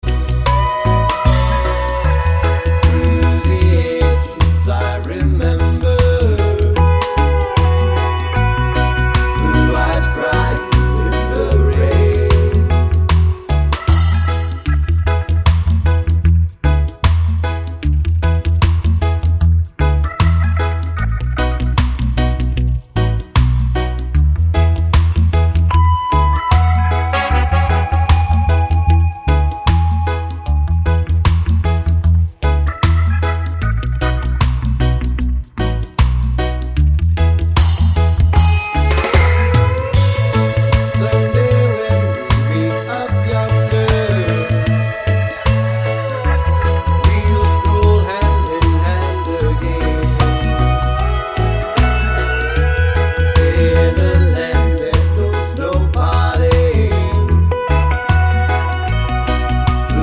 no Backing Vocals Reggae 3:18 Buy £1.50